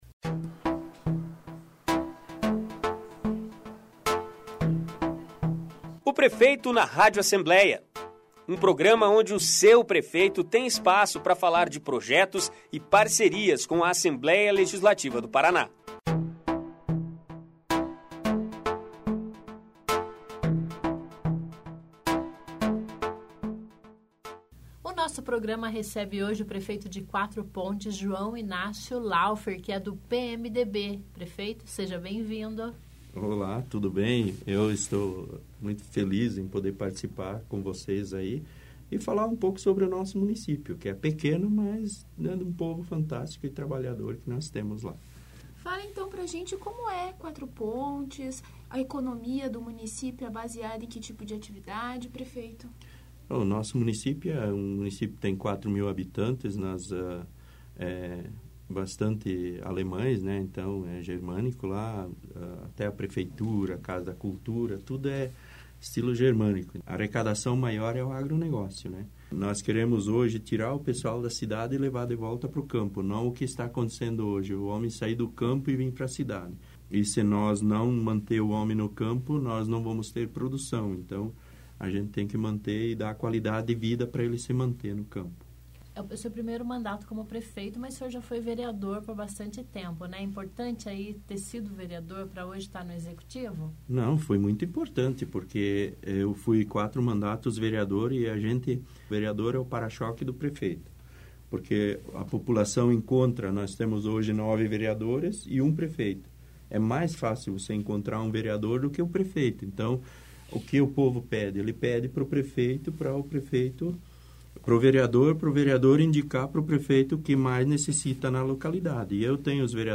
Ouça a deliciosa entrevista com o prefeito de Quatro Pontes, no Oeste do Paraná, João Inácio Laufer, ao programa "Prefeito na Rádio Alep" desta semana.